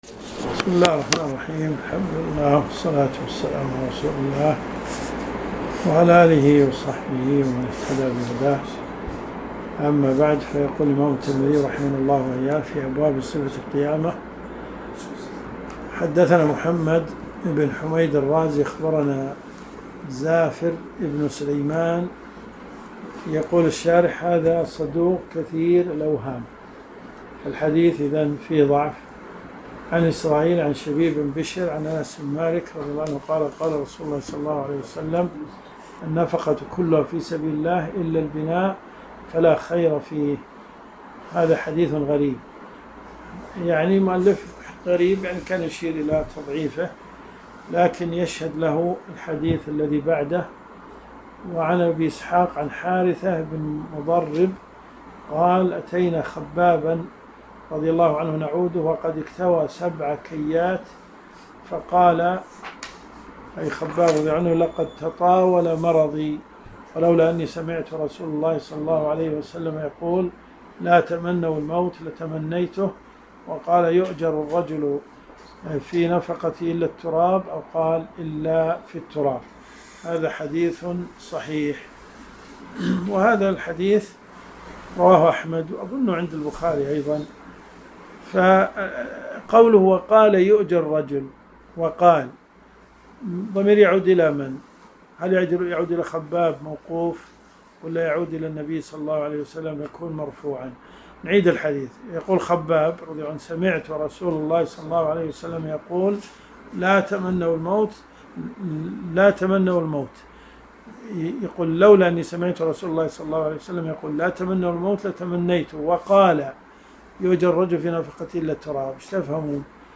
دروس صوتيه